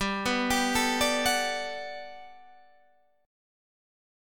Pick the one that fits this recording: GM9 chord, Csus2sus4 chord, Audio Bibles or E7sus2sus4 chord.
GM9 chord